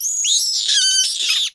rats_1.wav